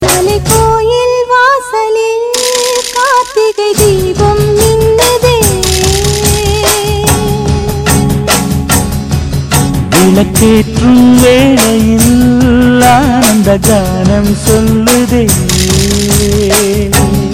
best flute ringtone download | bhakti song ringtone